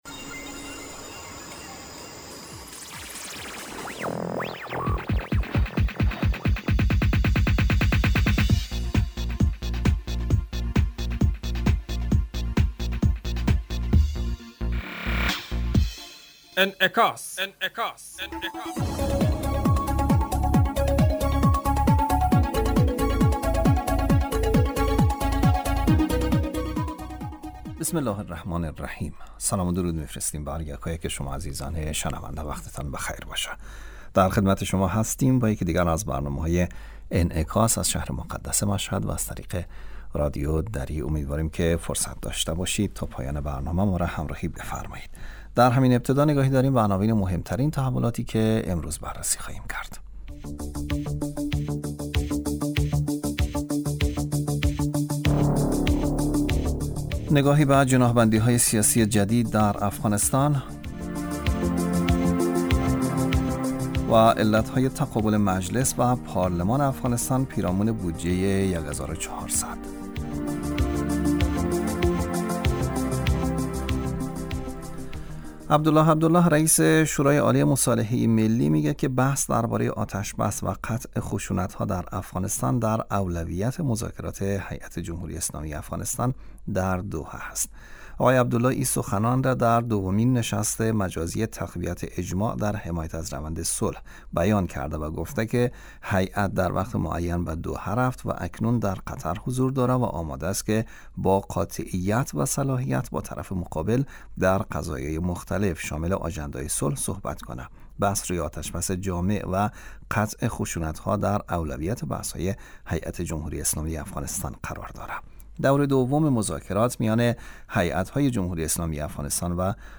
برنامه انعکاس به مدت 35 دقیقه هر روز در ساعت 12:00 ظهر (به وقت افغانستان) بصورت زنده پخش می شود.